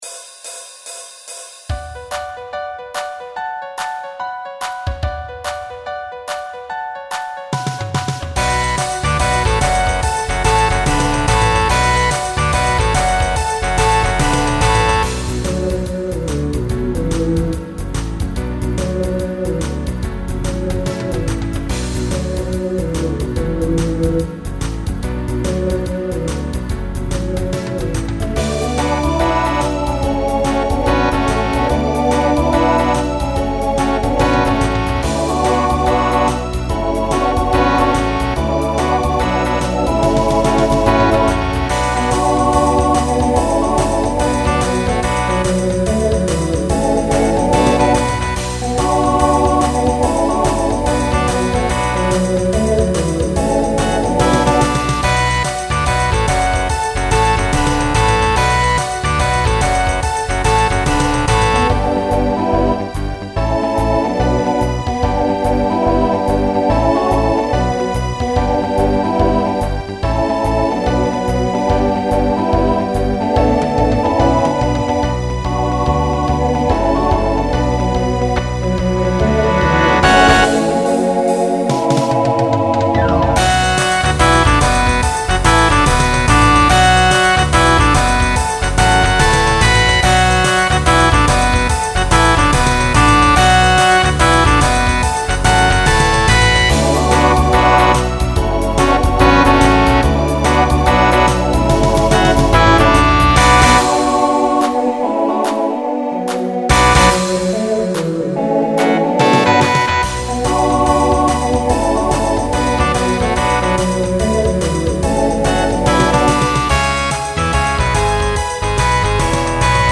Genre Rock Instrumental combo
Transition Voicing TTB